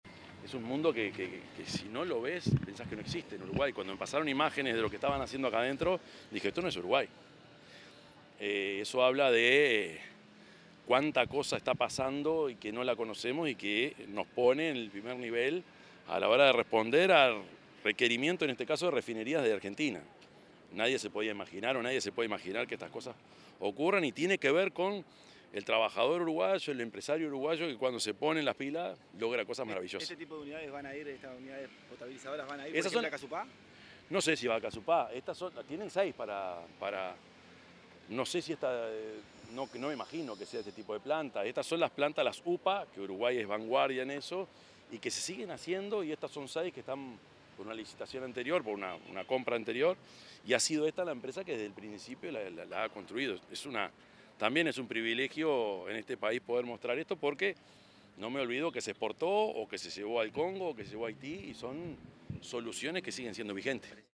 Declaraciones del presidente Yamandú Orsi
Tras participar en una visita a la planta industrial de CIR en Montevideo, el presidente de la República, Yamandú Orsi, dialogó con la prensa.